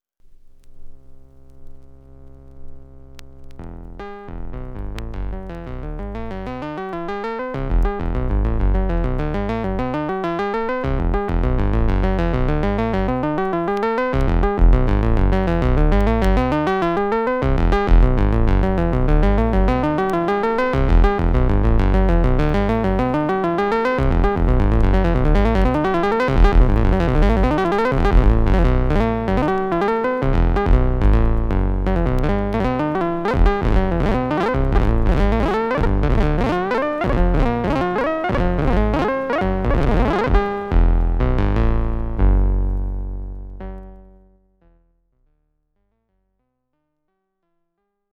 DJ mix at the LA party Spundae in the Circus Disco